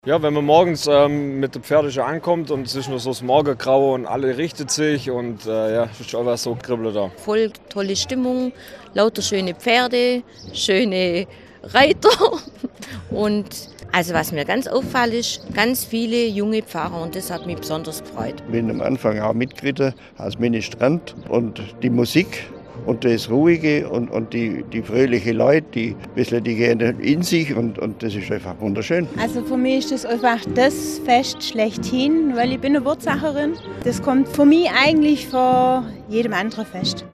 Am Freitagmorgen hat in Bad Wurzach (Kreis Ravensburg) das Heilig-Blut-Fest stattgefunden.
Stimmen von Besucherinnen und Besuchern: